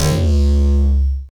Desecrated bass hit 02.wav